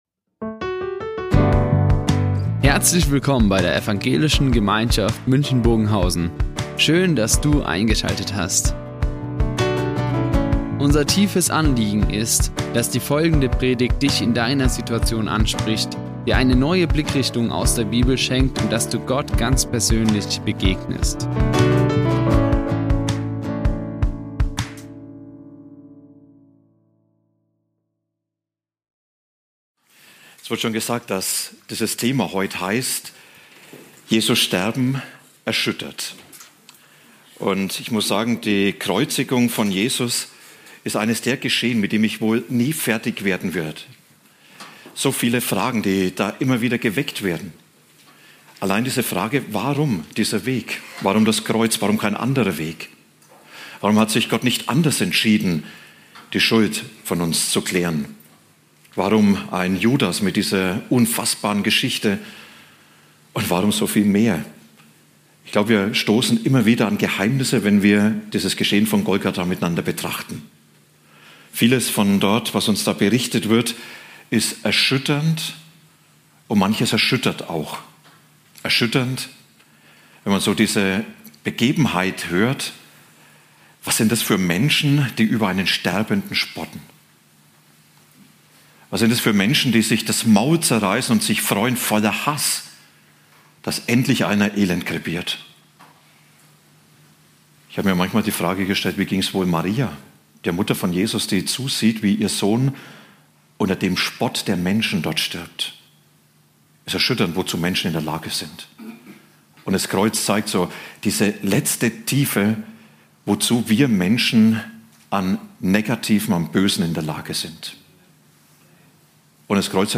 Jesu Sterben erschüttert | Karfreitagspredigt Matthäus 27, 33-54 ~ Ev. Gemeinschaft München Predigten Podcast